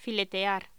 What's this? Locución: Filetear voz